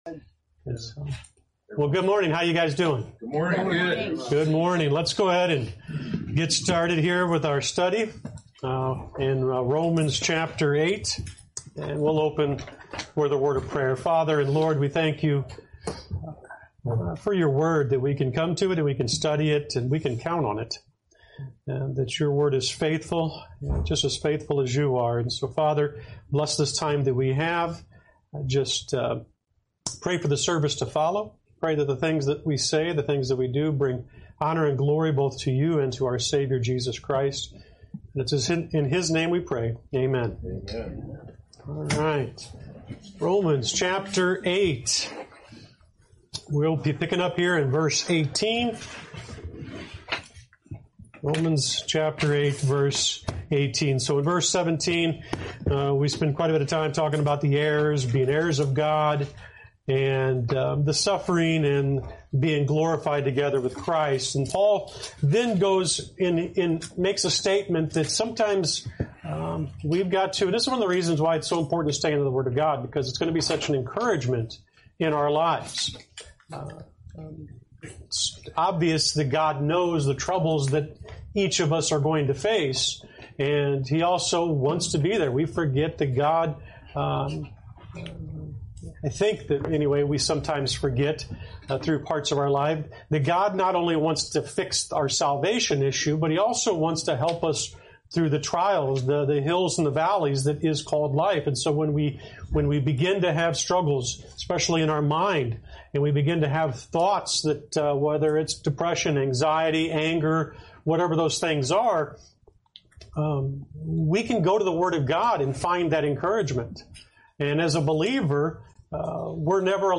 Lesson 47: Romans 8:18-25